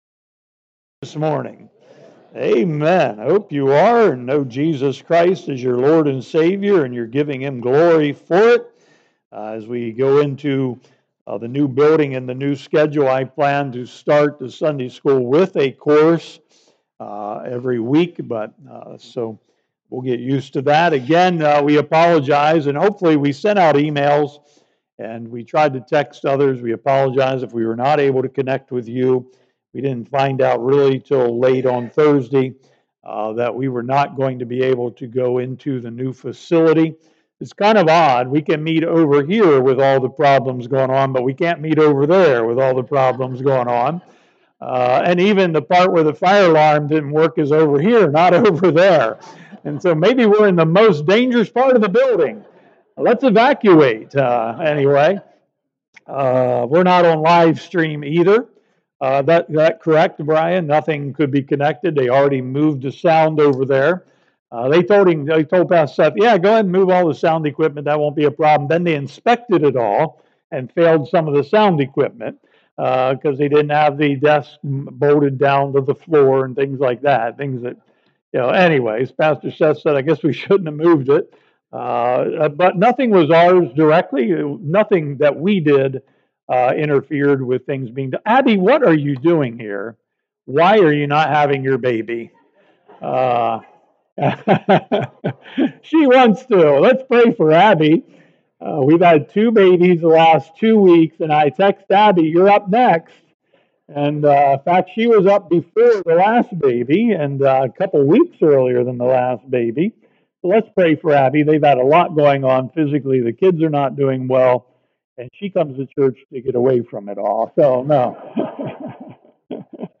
Sermons | Mt. Zion Baptist Church